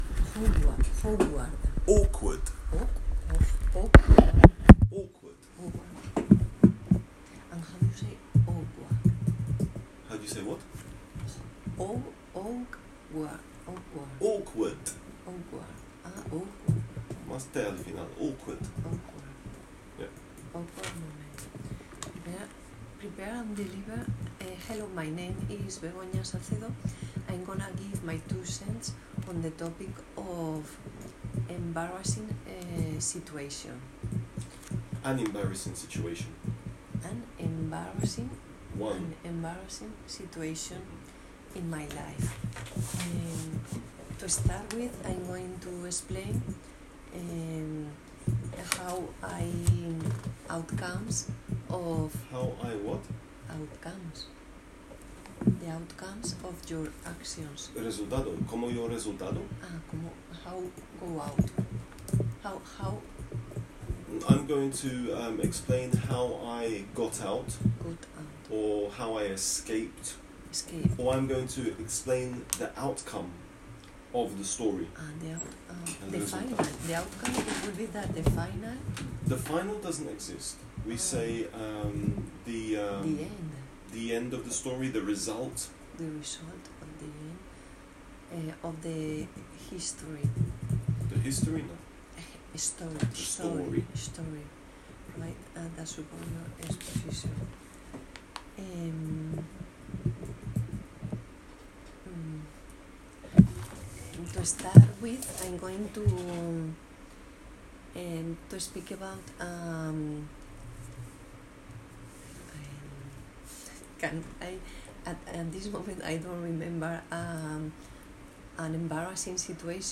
awkward-moments-b2-monologue-andalucia-2014.m4a